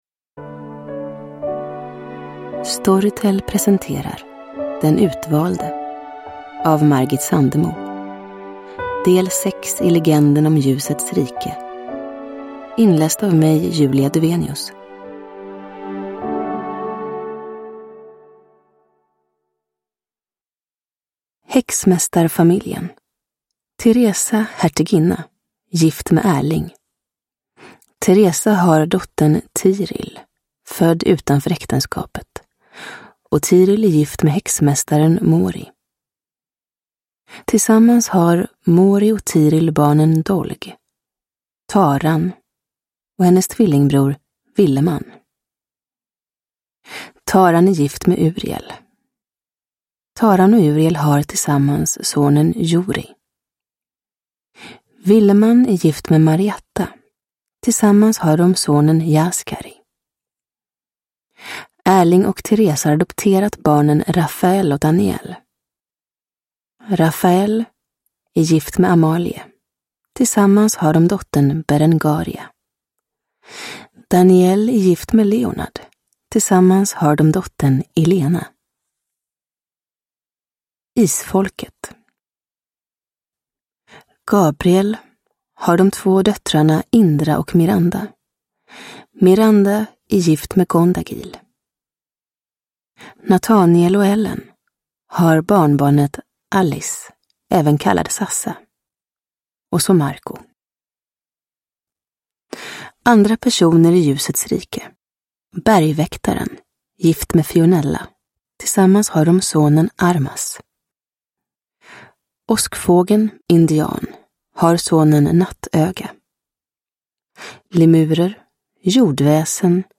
Den utvalde – Ljudbok – Laddas ner